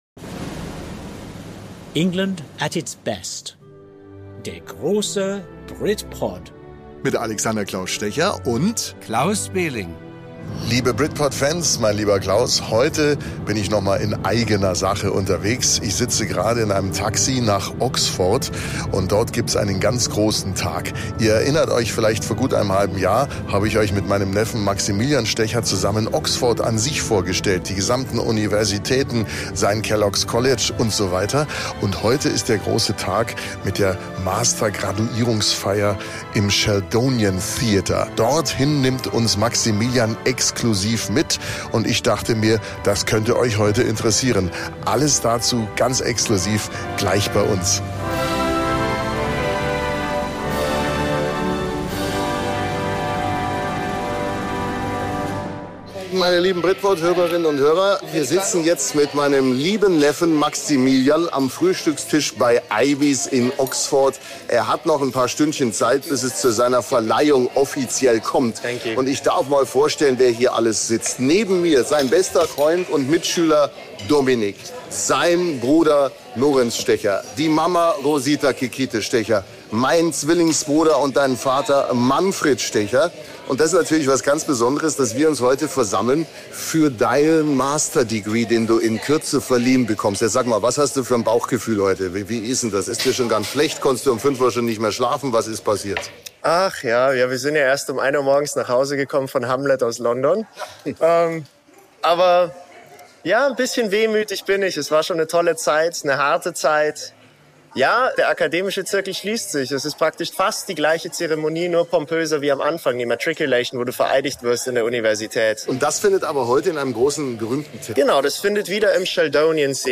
Lateinische Formeln hallen durch den Raum, Orgelklänge erfüllen das Rund, livrierte Diener führen die Graduands nach vorn.